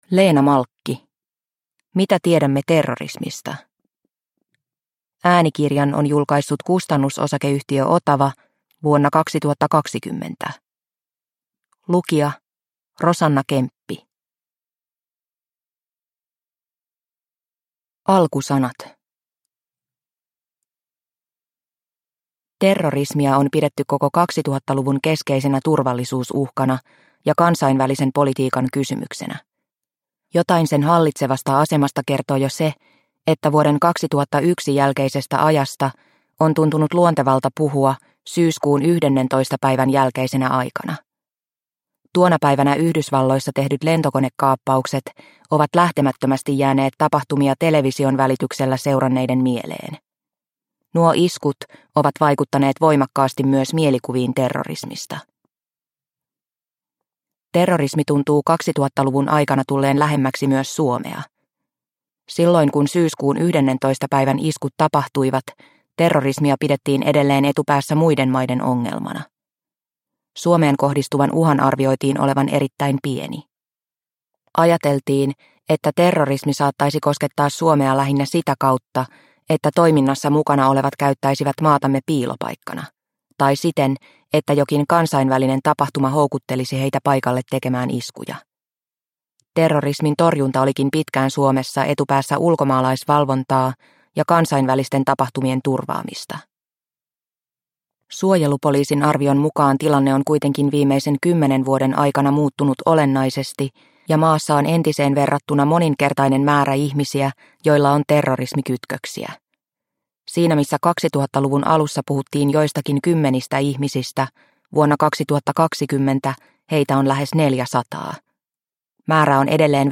Mitä tiedämme terrorismista – Ljudbok – Laddas ner